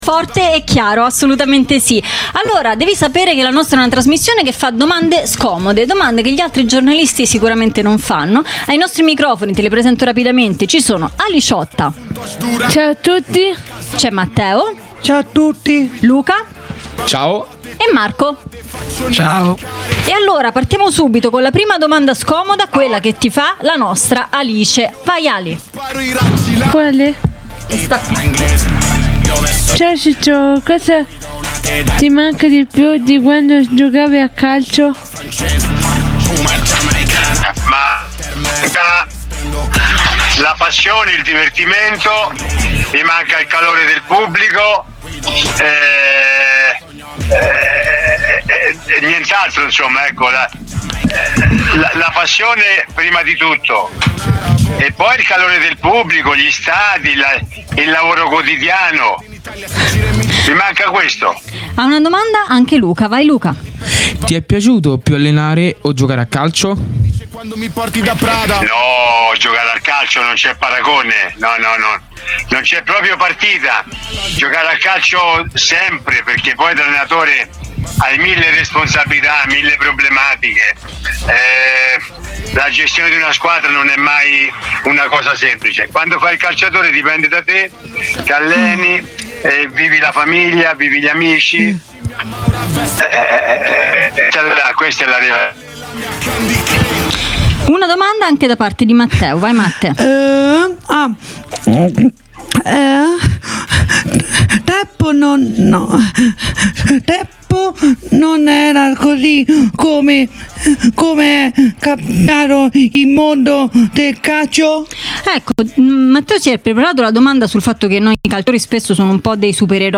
Jolly Roger - Puntata 24 - Intervista a Ciccio Graziani